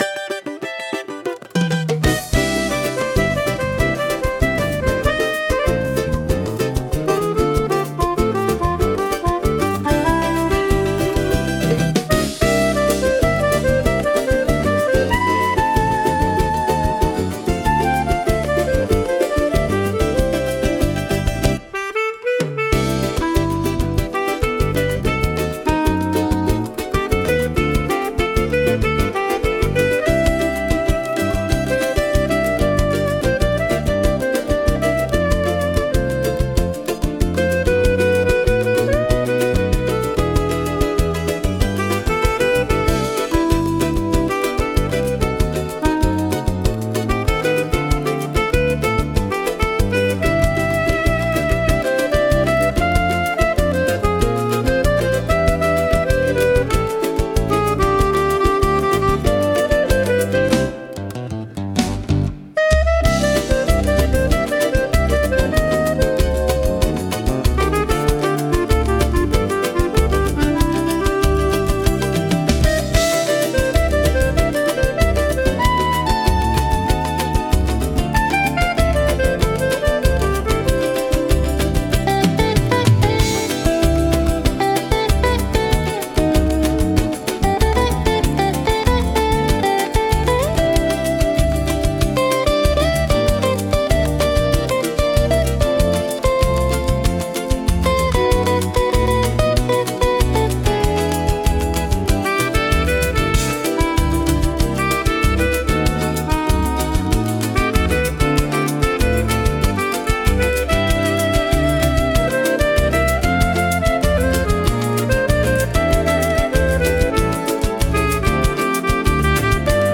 música e arranjo: IA) instrumental 10